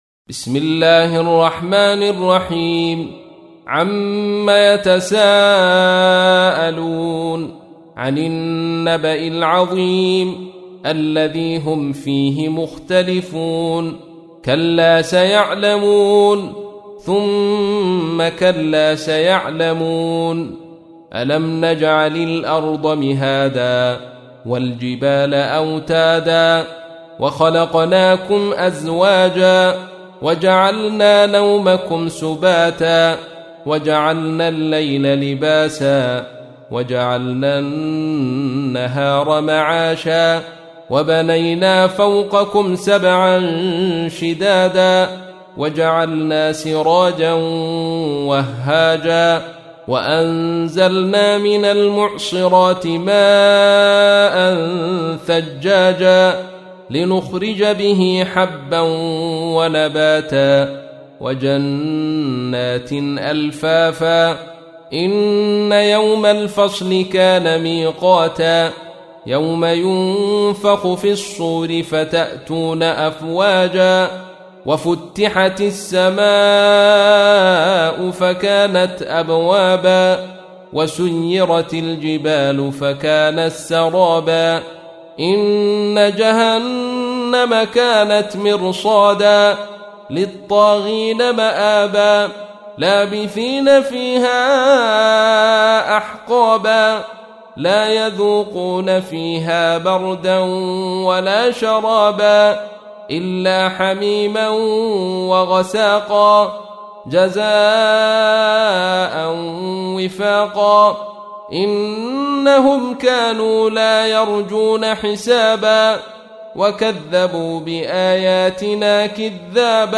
تحميل : 78. سورة النبأ / القارئ عبد الرشيد صوفي / القرآن الكريم / موقع يا حسين